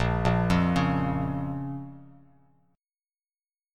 Bbm Chord
Listen to Bbm strummed